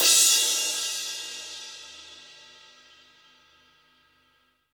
CYM 18 DRK0F.wav